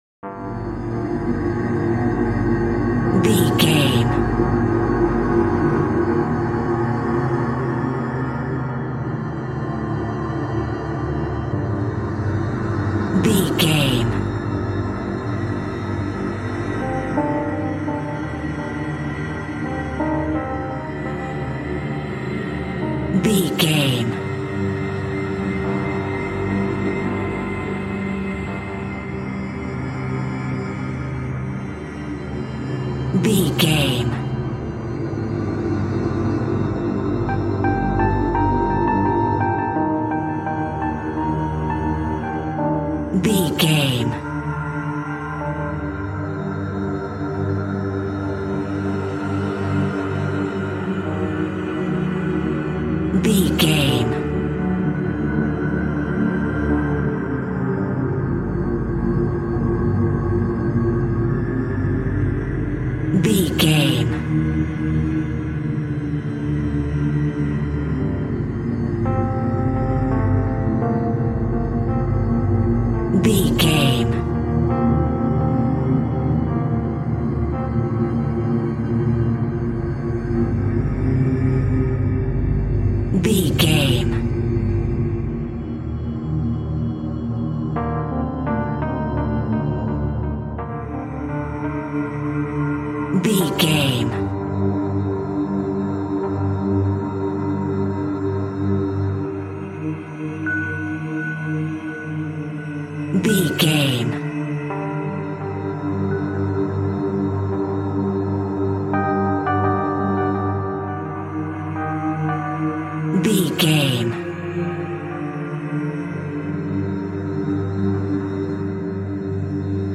Atonal
scary
ominous
dark
haunting
eerie
ethereal
dreamy
synthesiser
electric piano
Synth Pads
atmospheres